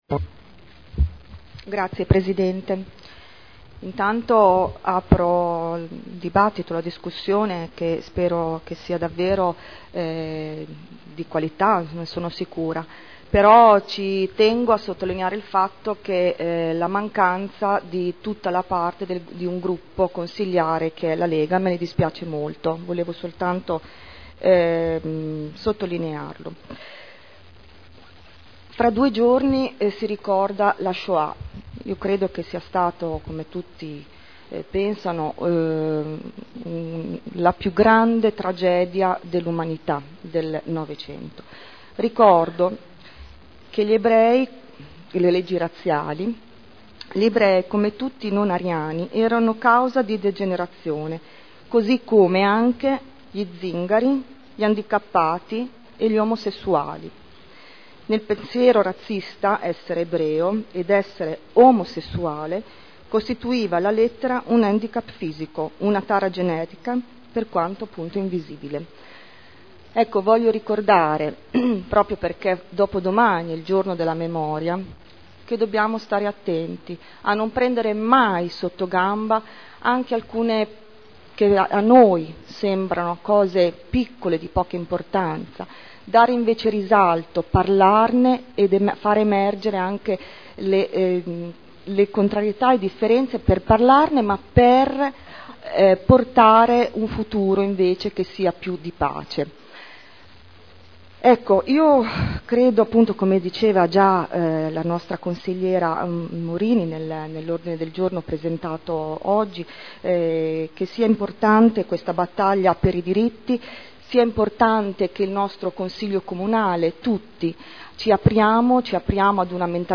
Ingrid Caporioni — Sito Audio Consiglio Comunale